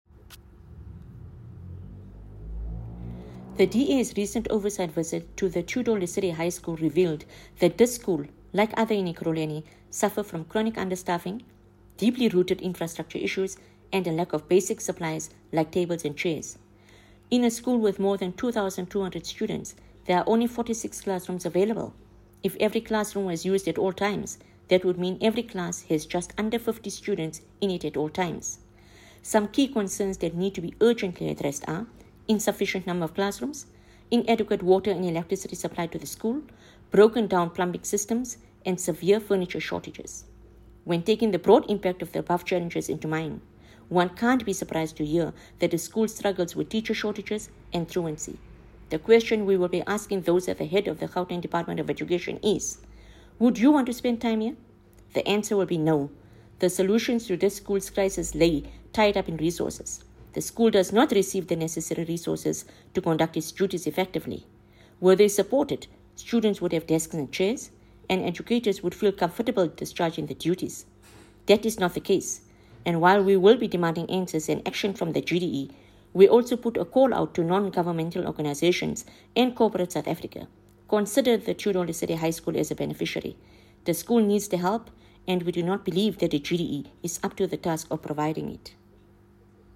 Note to Editors: Please find an English soundbite by Haseena Ismail MP